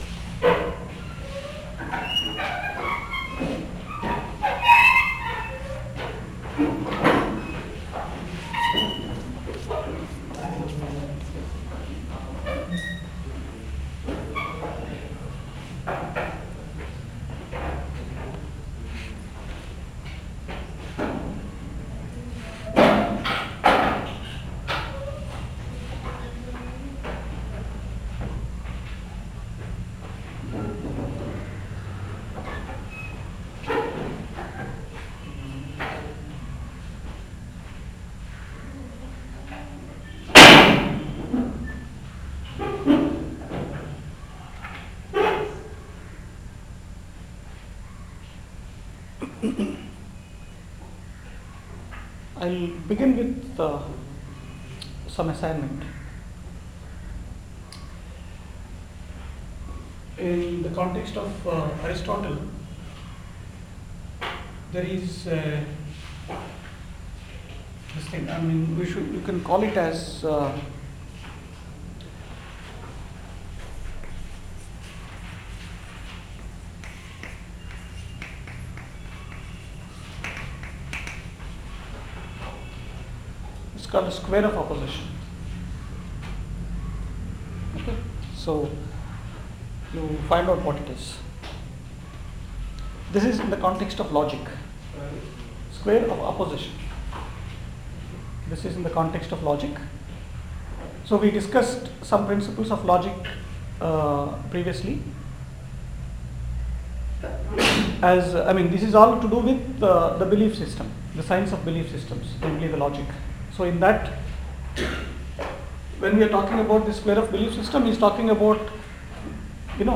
lecture 7